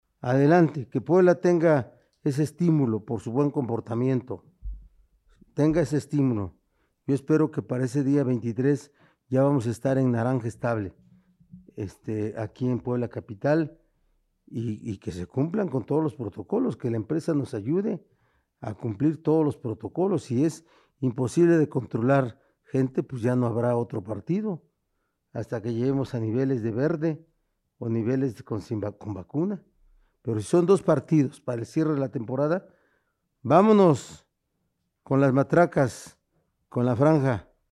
En conferencia de prensa, el Gobernador de Puebla, Miguel Barbosa Huerta, acompañado de la secretaria de Economía de la entidad, Olivia Salomón, explicaron este jueves que en cinco de los nueve sectores en que el IMSS cataloga su registro, se reportó recuperación en el rubro empleo asegurado en el estado durante septiembre 2020: Transporte y Comunicaciones, Industria de la Transformación, Comercio, Servicios Sociales y Comunales e Industrias Extractivas; sumaron 677 nuevas altas netas en septiembre.